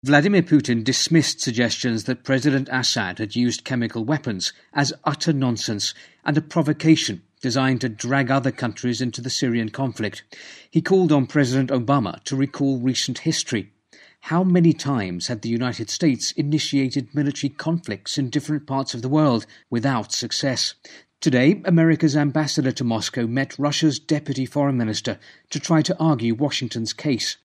【英音模仿秀】俄反对武力打击叙利亚 听力文件下载—在线英语听力室